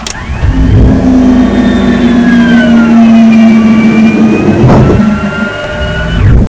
gear.wav